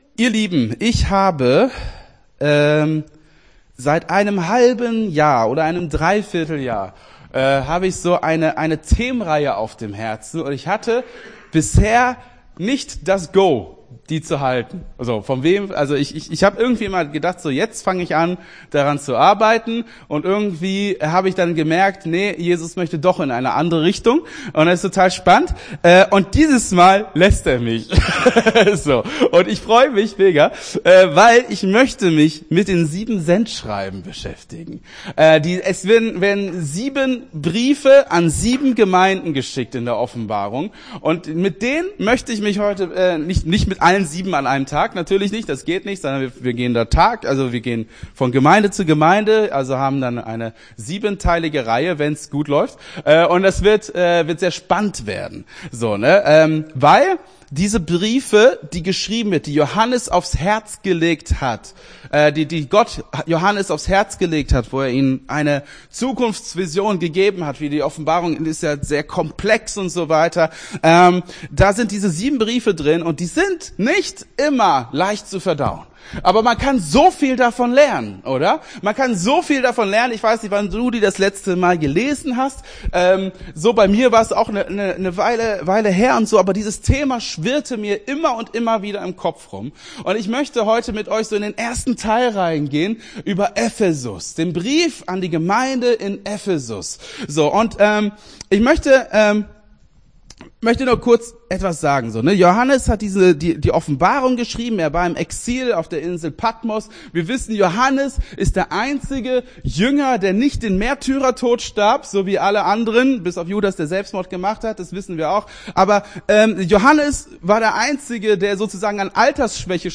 Gottesdienst 07.04.24 - FCG Hagen